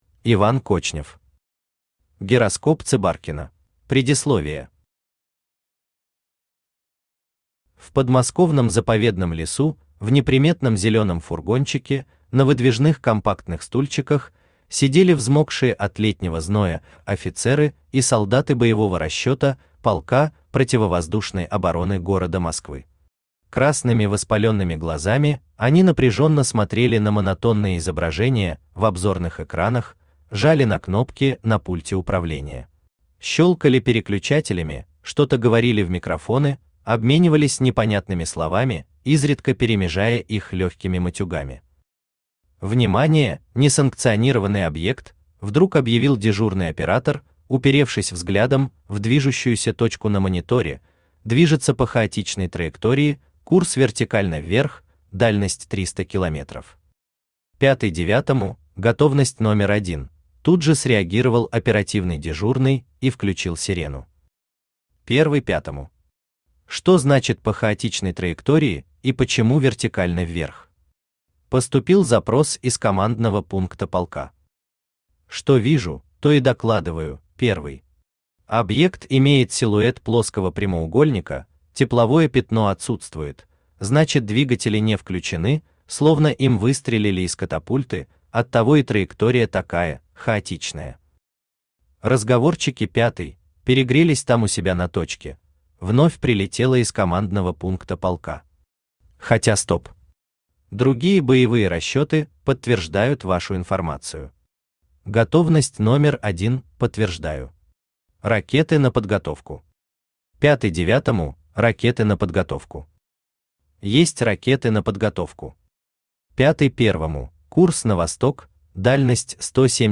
Аудиокнига Гироскоп Цибаркина | Библиотека аудиокниг
Aудиокнига Гироскоп Цибаркина Автор Иван Кочнев Читает аудиокнигу Авточтец ЛитРес.